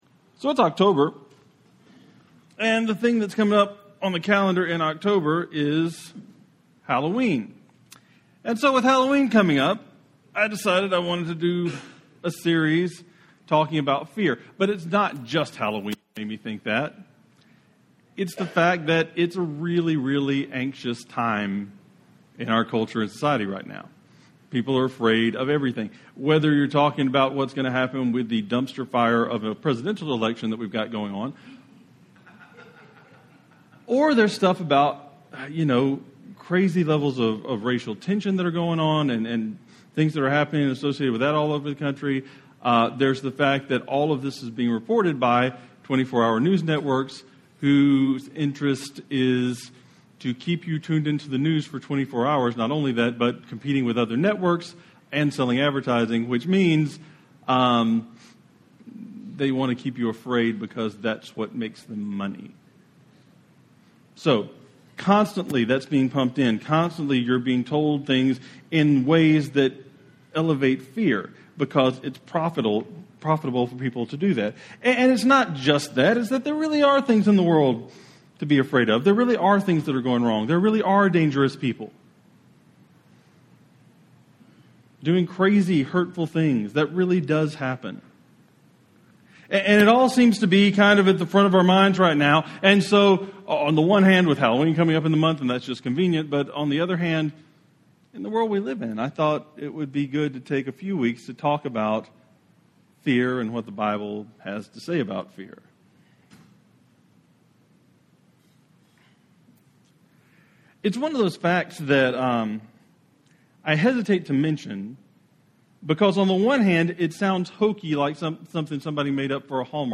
A sermon about having faith in a God who insists that we don't have to be afraid.